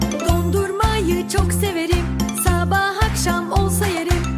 Play, download and share Dondurmayı severim original sound button!!!!
ice-cream-donate-sound.mp3